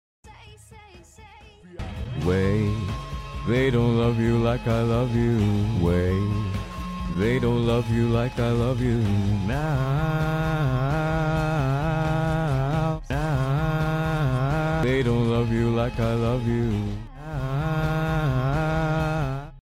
singing with love for you